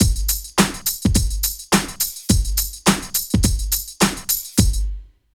18 DRUM LP-L.wav